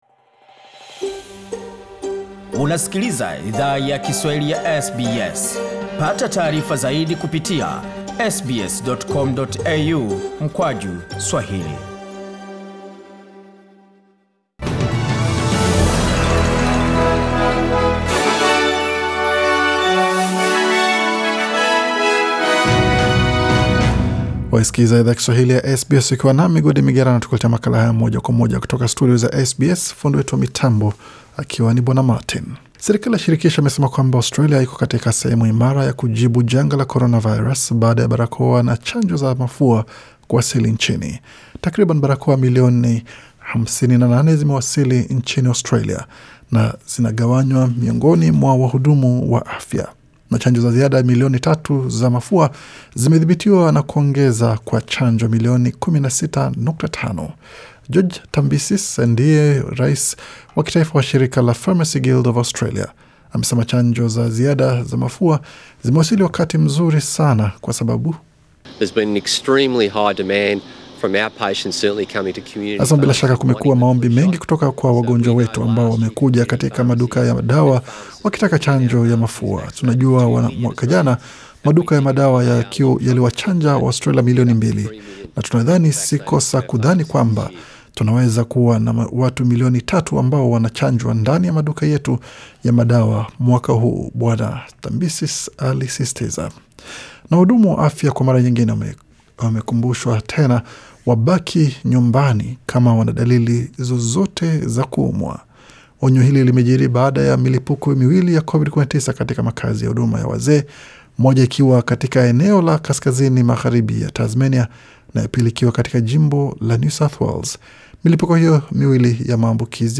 Taarifa za habari:Shughuli yakuwatafuta manusura zaendelea Uvira, DR Congo